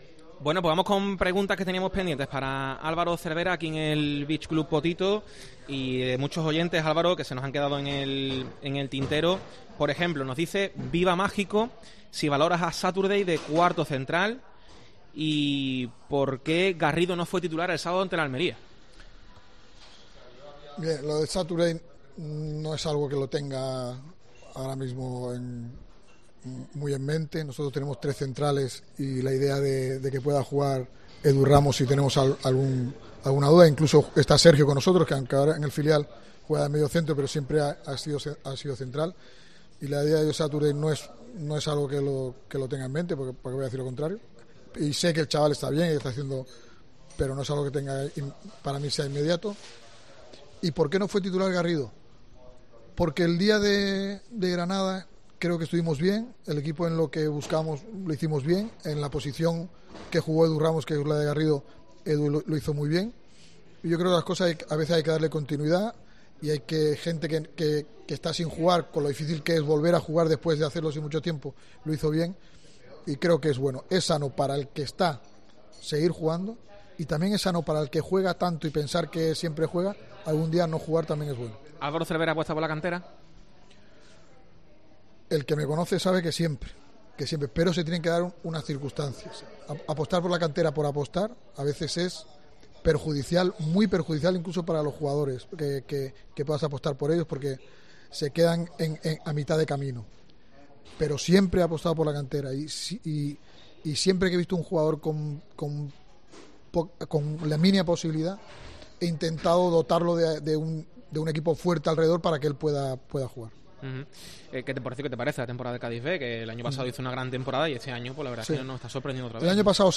El técnico del Cádiz responde a las preguntas de los oyentes y se moja sobre temas importantes
Entrevista a Álvaro Cervera en COPE (2ª parte)